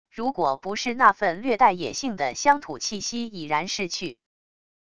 如果不是那份略带野性的乡土气息已然逝去wav音频生成系统WAV Audio Player